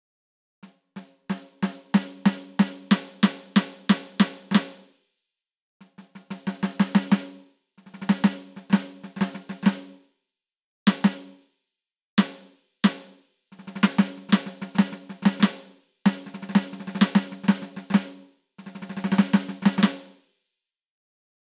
Die Snare Drum reagiert äußerst präzise und naturgetreu.
Die Samples sind generell sehr obertonreich und bieten ein variables Abbild.
Mahagoni Snare
roland_td-17k-l_test__snare_mahagony.mp3